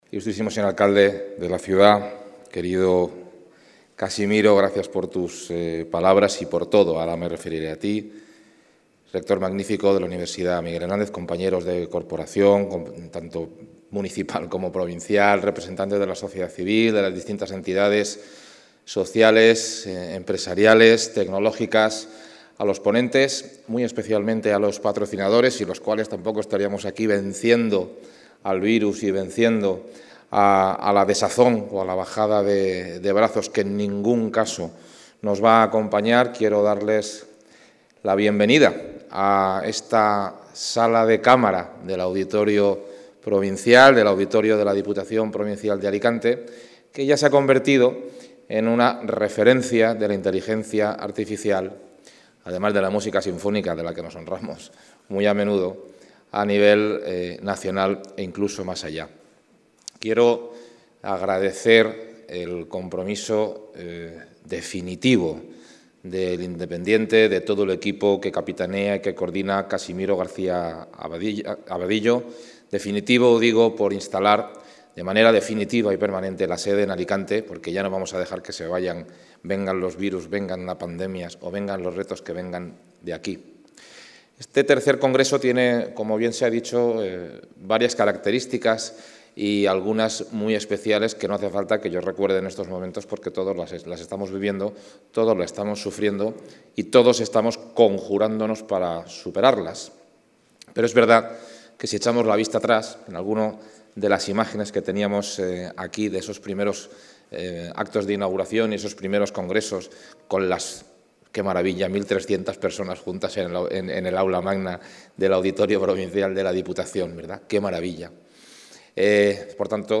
El presidente de la Diputación inaugura en el ADDA el III Congreso de Inteligencia Artificial que impulsa Suma Innova y organiza El Independiente
Debido a las restricciones sanitarias derivadas de la pandemia, el congreso se celebra durante toda la mañana en formato híbrido, con asistencia presencial y online.
III-Congreso-IA-ADDA-inauguracion-Carlos-Mazón.mp3